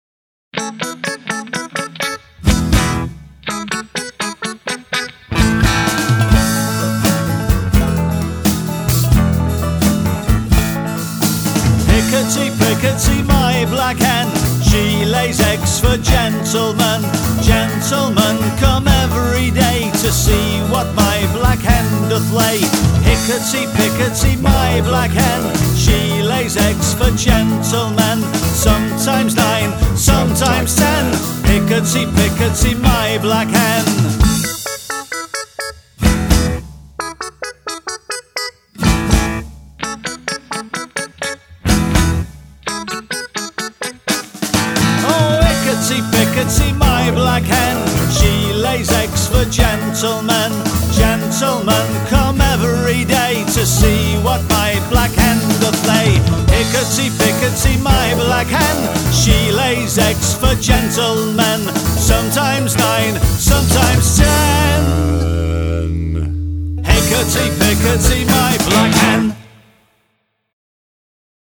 Fun & Punk